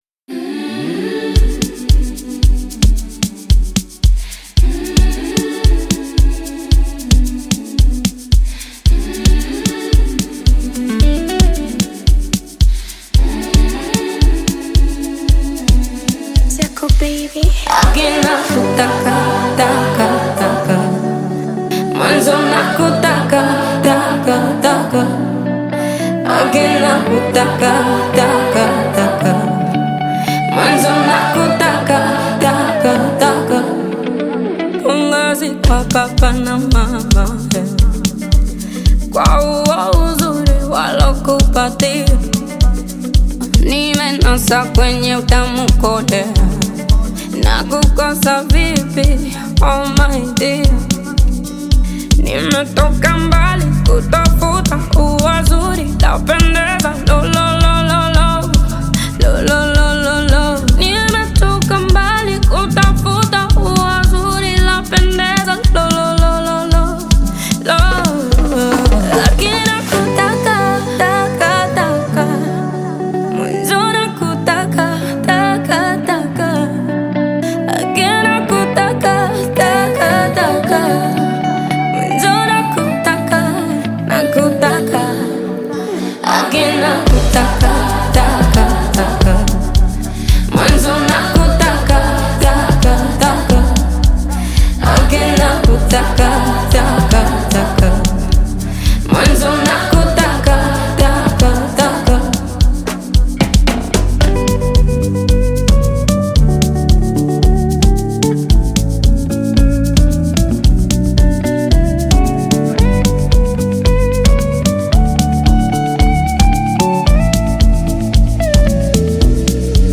smooth vocals, catchy melodies, and modern production